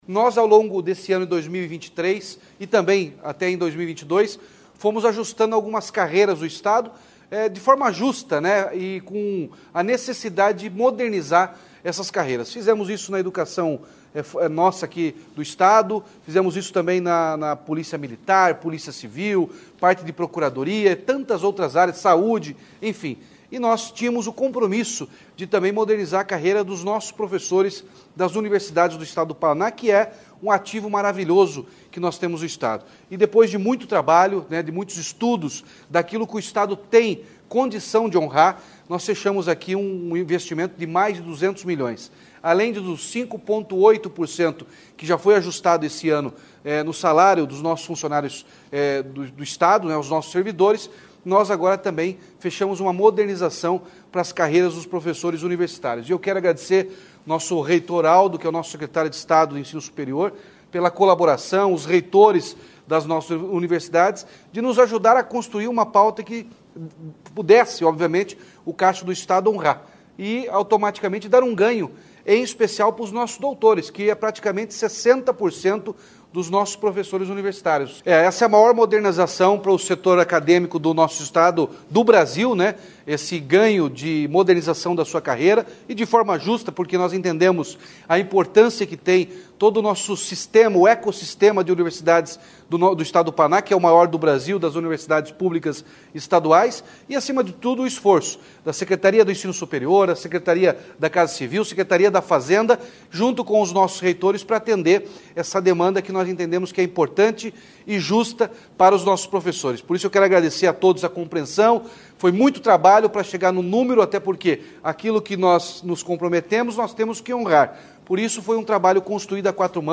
Sonora do governador Ratinho Junior sobre o aumento do adicional de titulação para professores da rede de ensino superior do Paraná